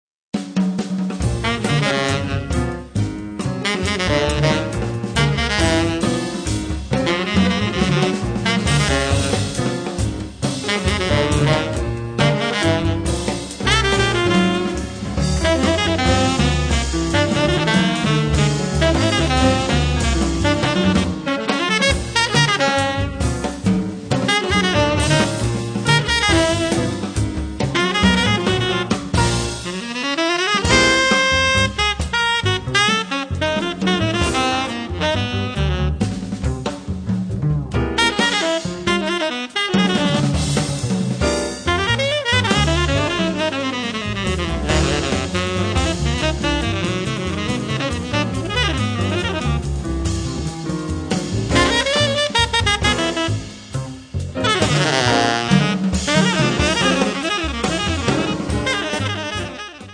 piano, hammond
tenorsax
contrabbasso
batteria